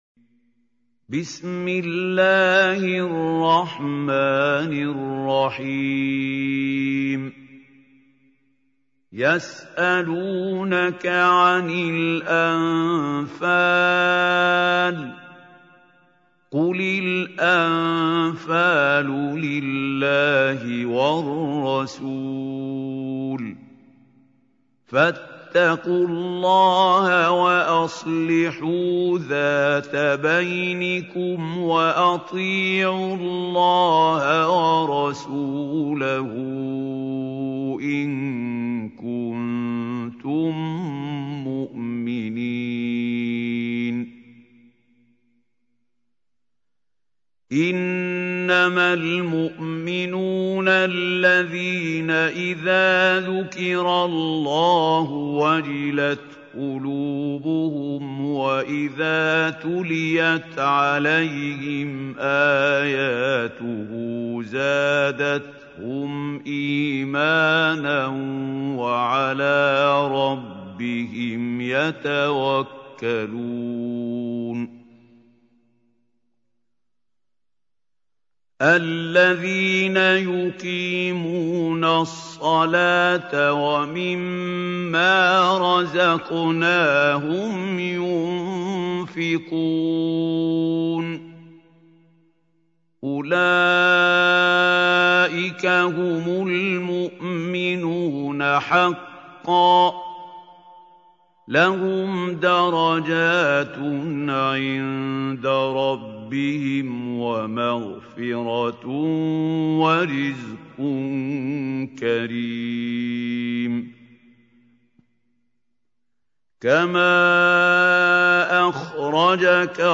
Surah Al-Anfal Recitation by Mahmoud Al Hussary
Listen online and download mp3 tilawat / Recitation of Surah Al Anfal in the voice of Sheikh Mahmoud Khalil Al Hussary.